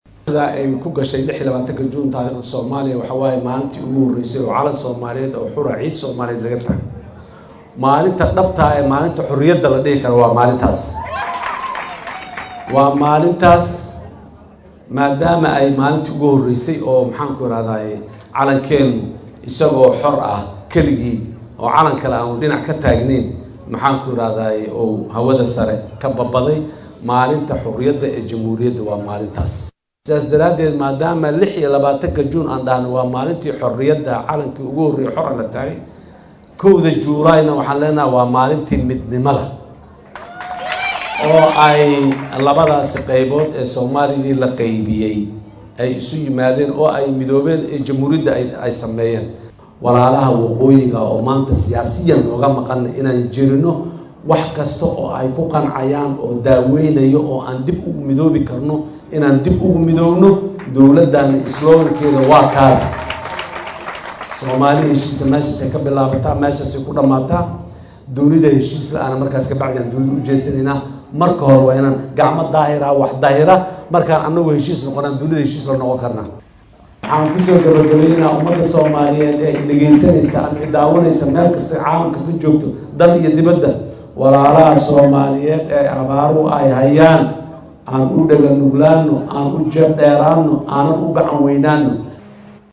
Munaasabad ballaaran oo lagu qabtay xarunta aqalka Dowladda Hoose ee Muqdisho, laguna maamuusayo muhiimadda maalintan ku astaysan midowgii labada gobol iyo ka xoroobiddii gumaystihii Talyaaniga ee gobollada Koonfureed, ayaa waxaa ka qayb galay madaxda dowladda Federaalka Soomaaliya oo uu ugu horreeyo Madaxweyne Xasan Sheekh Maxamuud, ra’iisul wasaaraha Soomaaliya Xamse Cabdi Barre, Guddoomiyayaasha Labada Aqal ee BJFS, Golaha Wasiirrada, Maamulka Gobolka Banaadir, marti sharaf iyo diblomaasiyiin lagu casuumay munaasabadda.
Madaxweyne Xasan Sheekh Maxamuud oo ka hadlay Munaasabadda xuska 1-da Luulyo ayaa Ummadda Soomaaliyeed ugu hambalyeeyay qiimaha ay maalintan u leedahay bulshada Soomaaliyeed, isagoo u rajeeyay in sanadkan kiisa kale ay ku gaaraan Nabad iyo Barwaaqo.